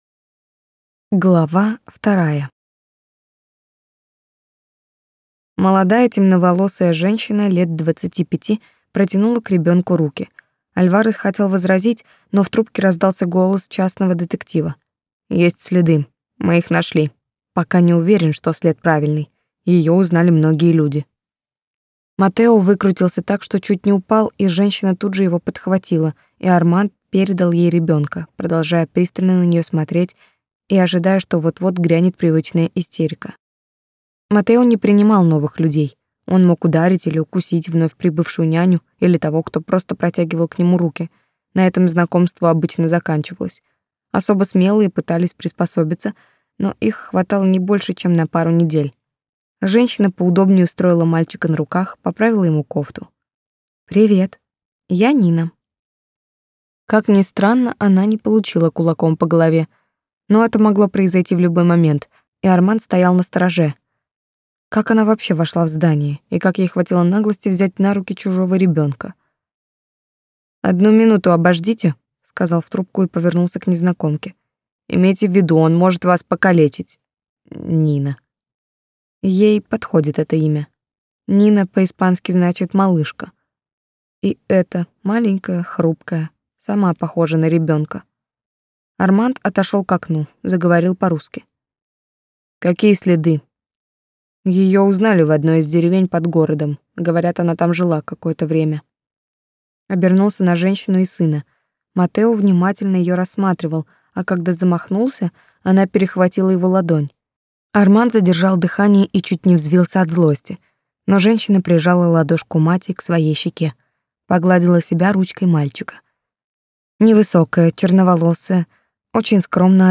Аудиокнига Краденое счастье. Книга 2 | Библиотека аудиокниг